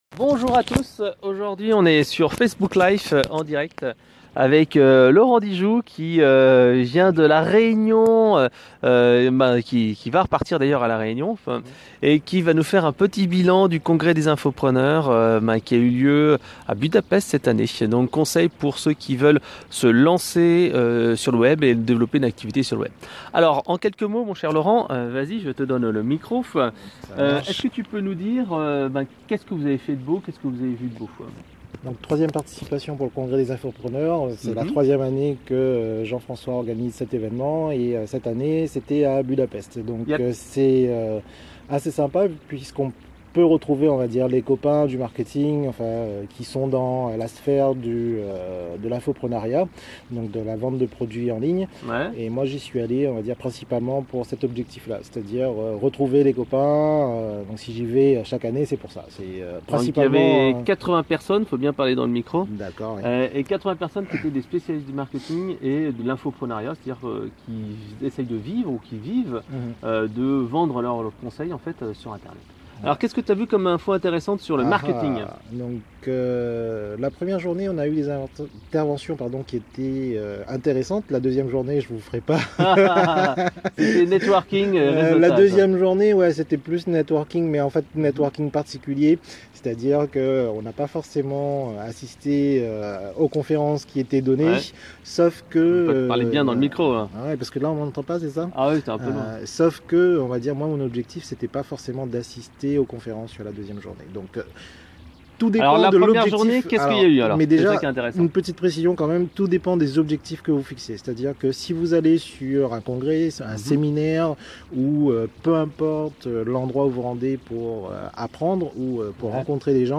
Comment générer du trafic en B2B - Interview